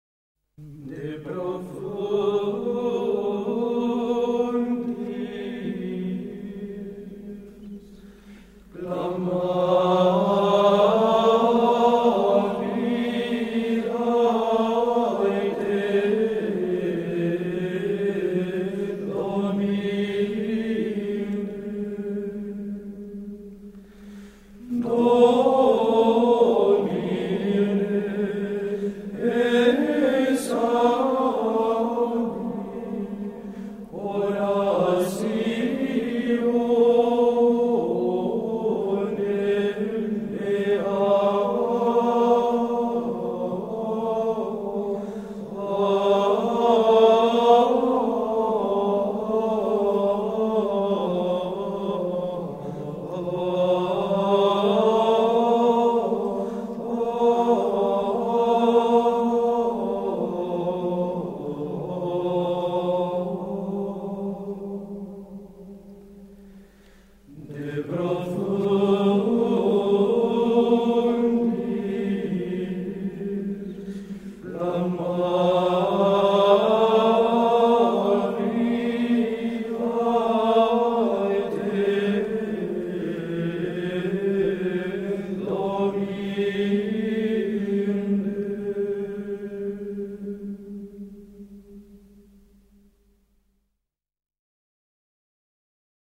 ♬ Ps 130 (129) De profundis – La voix des monastères disque 1 plage 3 (antienne d’offertoire)  1’34